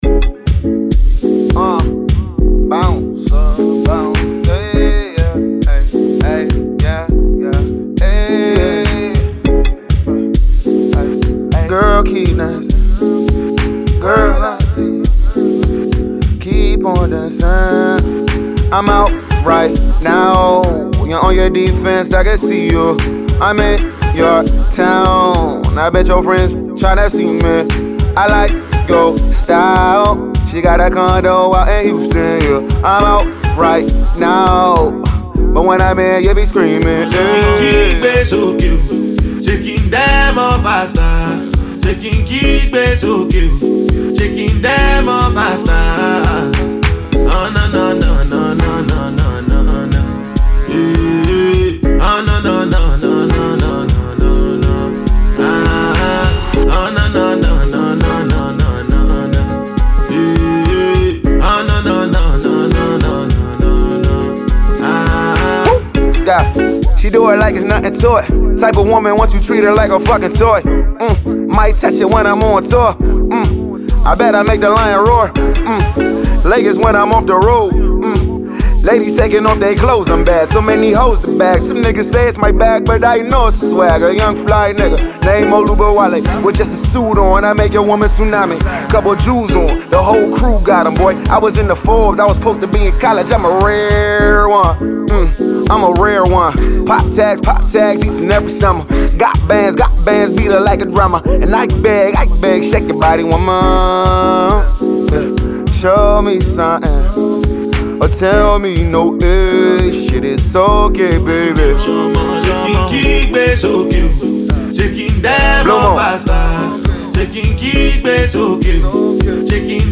Naija Music